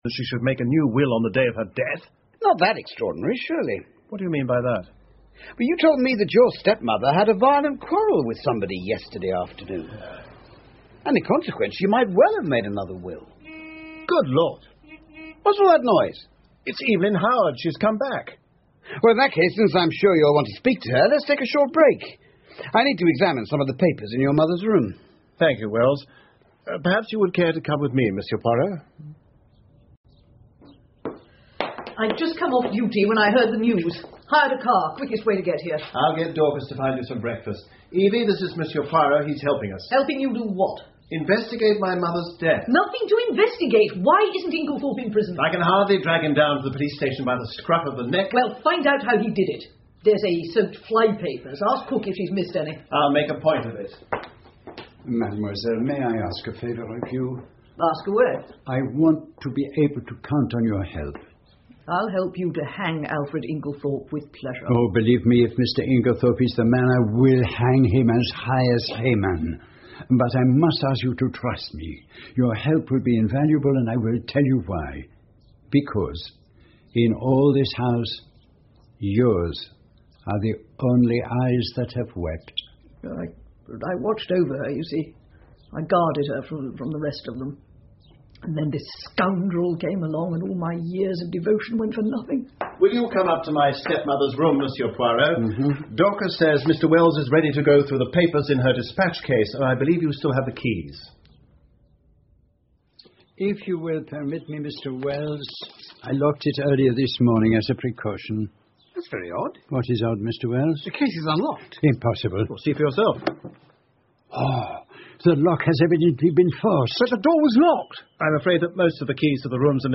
英文广播剧在线听 Agatha Christie - Mysterious Affair at Styles 11 听力文件下载—在线英语听力室